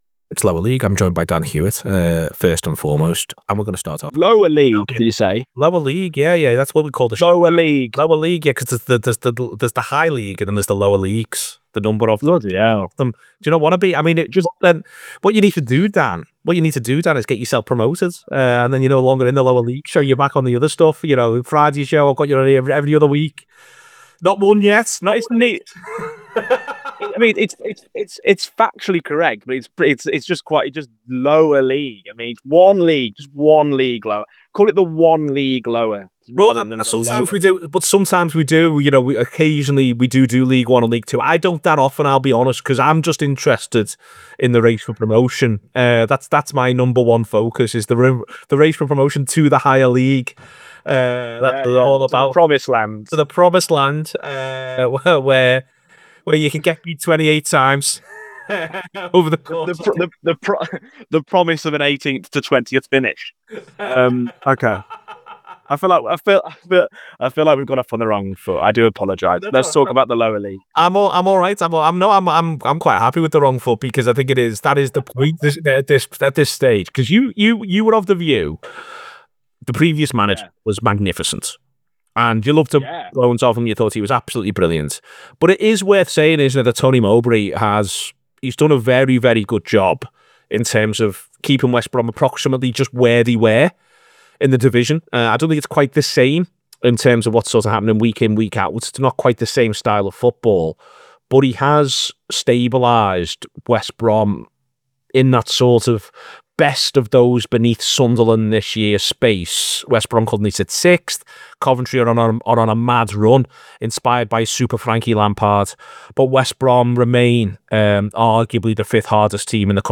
As the race for promotion gets even hotter we chat to fans of West Brom, Sheffield United, Sunderland and Leeds United to give their latest on their teams.